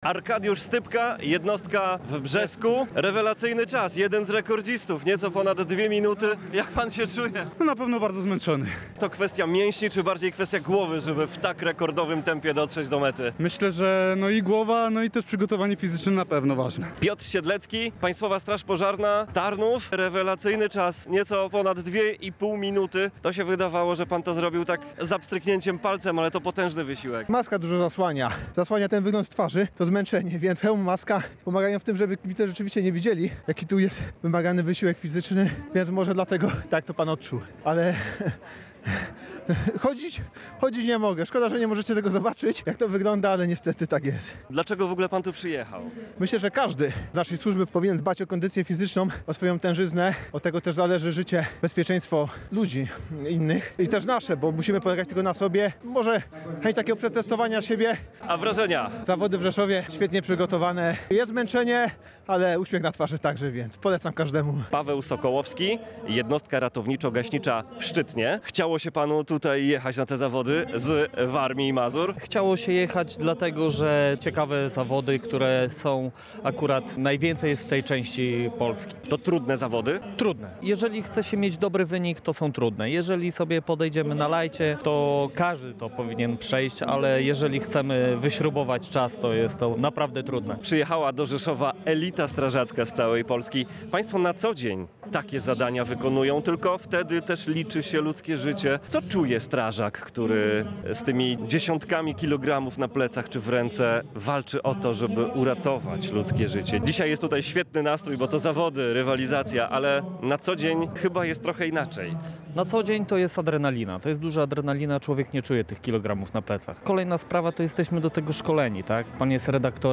Wydarzenie odbywa się na parkingu przed Urzędem Wojewódzkim w Rzeszowie.